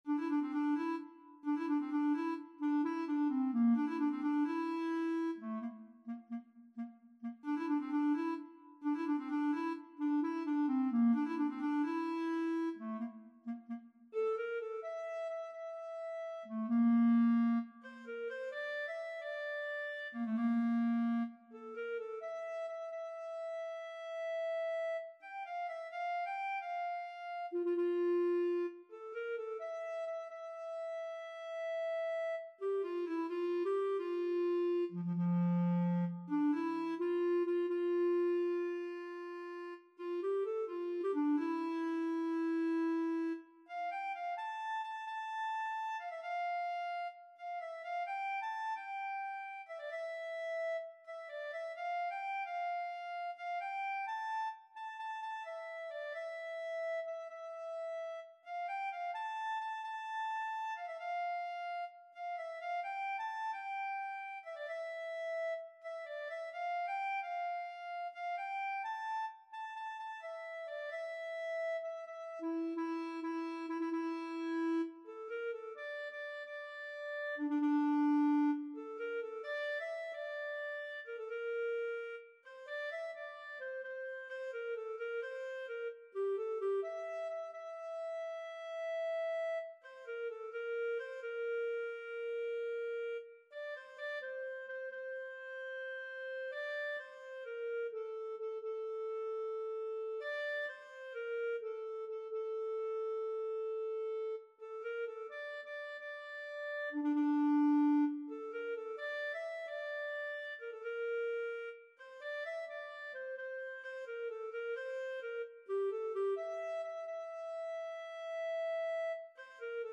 تنظیم شده برای کلارینت سی بمل